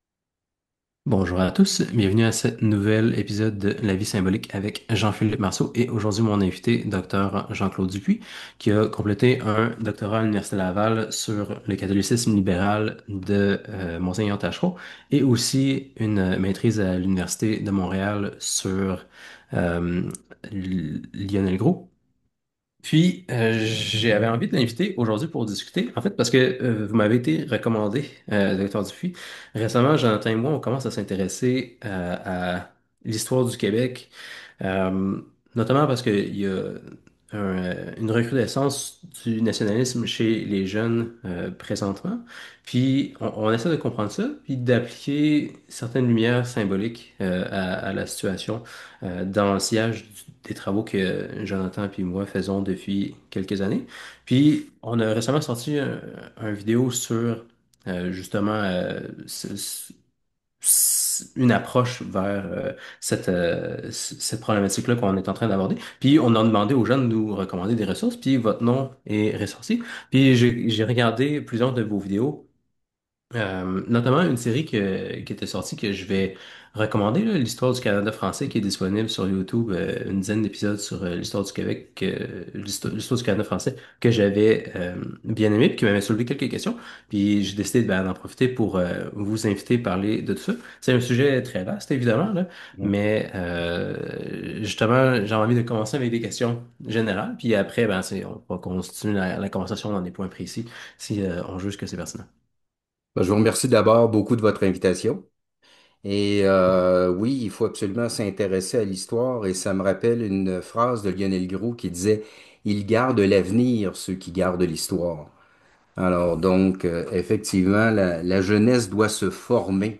La révolution tranquille comme conquête anglaise | Entrevue